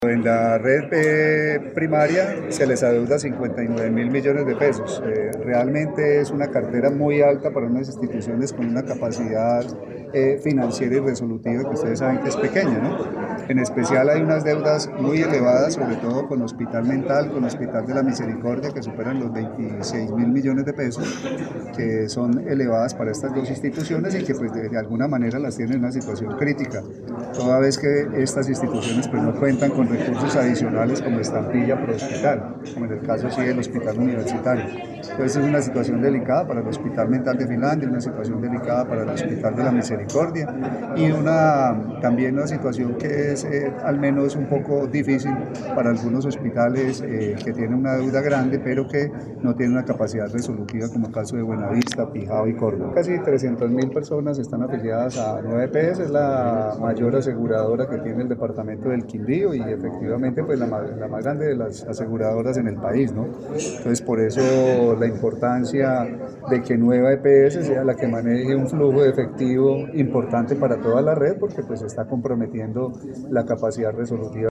Voz Carlos  Alberto Gómez Chacón, secretario de Salud del Quindío, rueda de prensa Nueva EPS:
Audio-Carlos-Alberto-Gomez-Chacon-secretario-de-Salud-del-Quindio-rueda-de-prensa-NUEVA-EPS.mp3